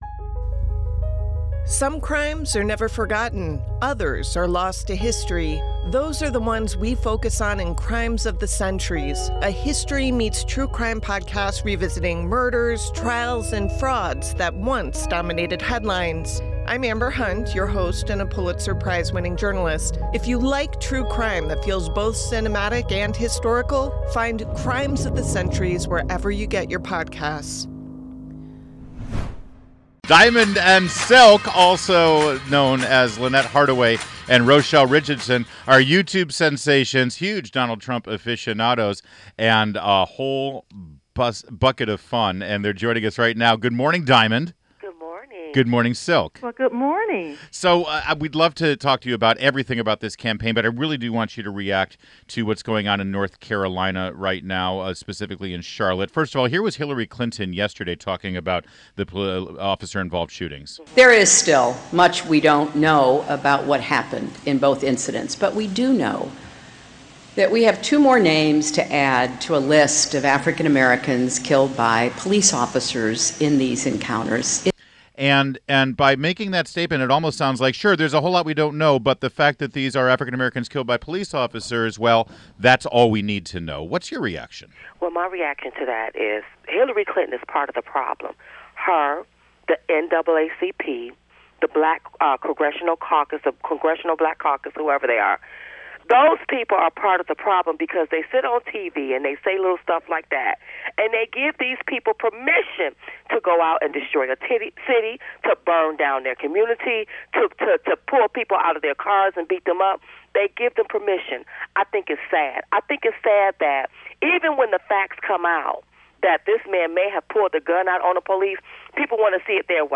WMAL Interview - Diamond and Silk - 09.22.16